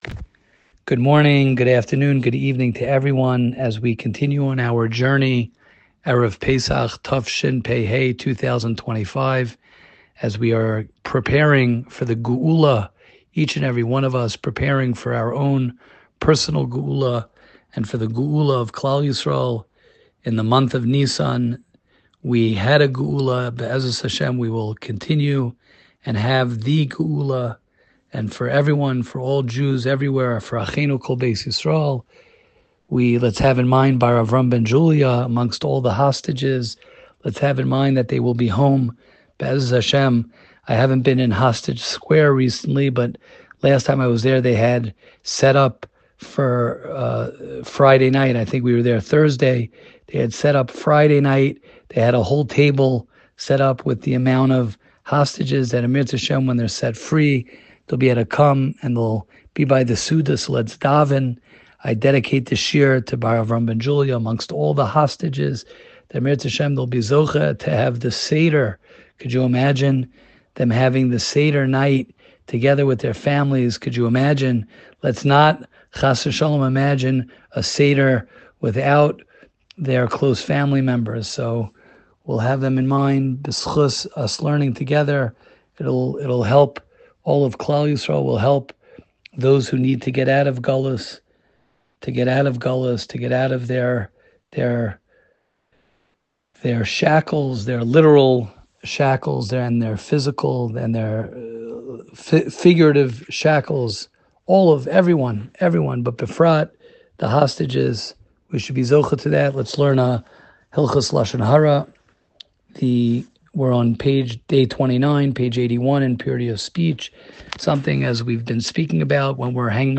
Bein Hazmanim Shiurim